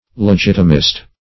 Search Result for " legitimist" : The Collaborative International Dictionary of English v.0.48: Legitimist \Le*git"i*mist\ (-m[i^]st), n. [Cf. F. l['e]gitimiste.] 1.
legitimist.mp3